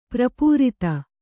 ♪ prapūrita